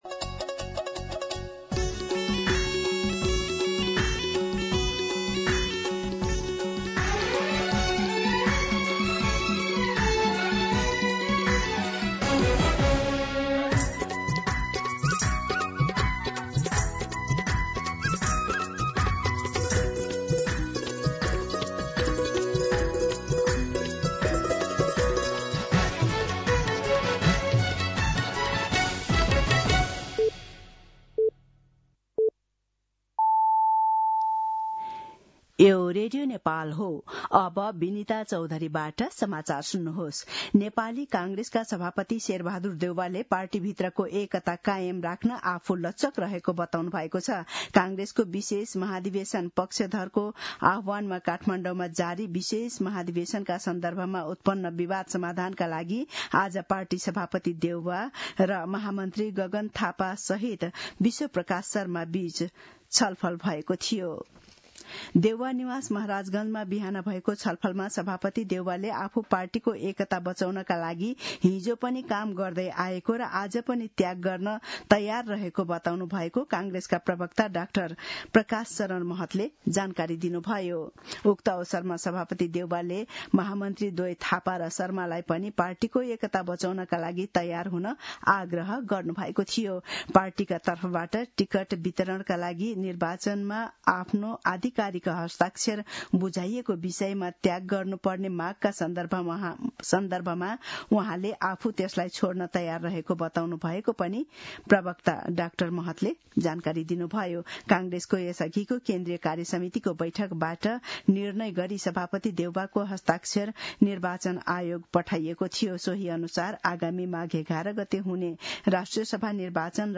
मध्यान्ह १२ बजेको नेपाली समाचार : ३० पुष , २०८२